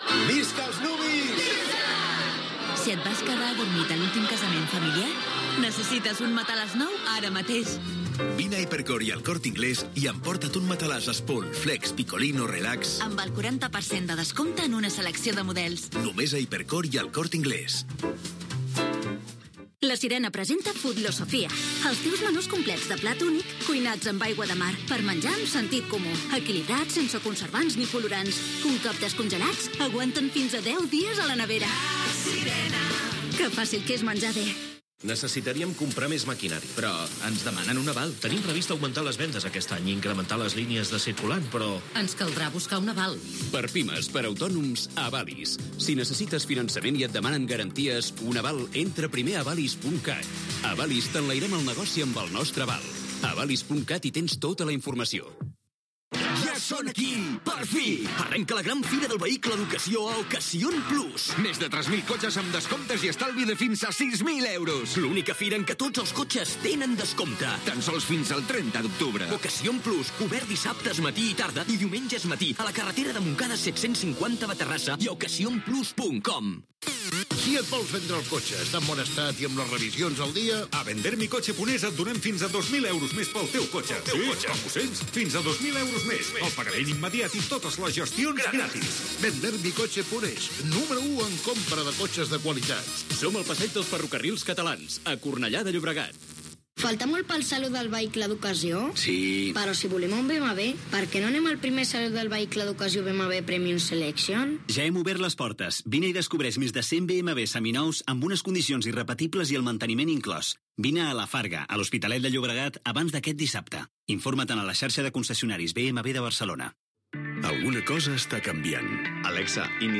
Publicitat, promoció del "skill" d'Alexa i Catalunya Ràdio, indicatius de l'emissora i del programa, connexió amb la unitat mòbil que està a lautopista AP 7, al Penedès, seguint la columna de Tarragona. Era una de les Marxes per la Llibertat que recorrien Catalunya per protestar sobre la setència del judici als implicats en el Referèndum d'autodeterminació de Catalunya de l'1 d'octubre de 2017